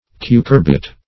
Cucurbit \Cu*cur"bit\ Cucurbite
\Cu*cur"bite\(k[-u]*k[^u]r"b[i^]t), n. [L. cucurbita a gourd: